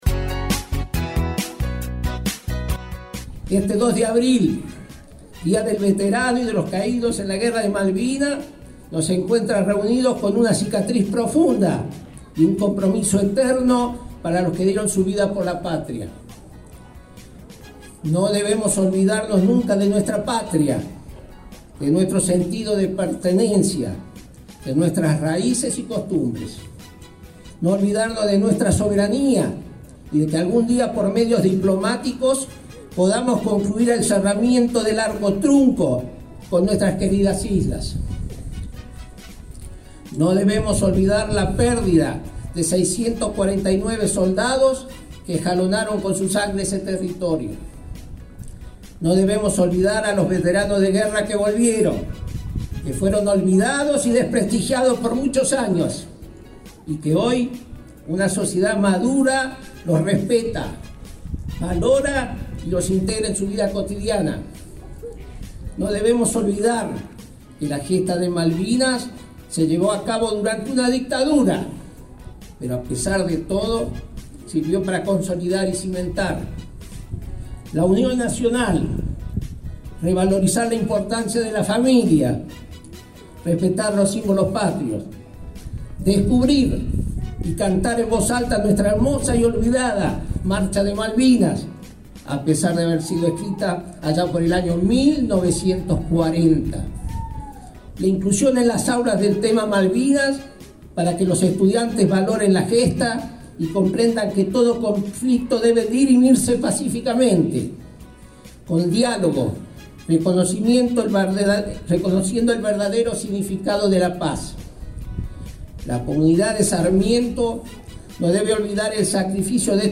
Este miércoles en la localidad de Sarmiento, se vivió un sentido acto en conmemoración por 43° Aniversario “Día del Veterano y Caídos en la Guerra de Malvinas”.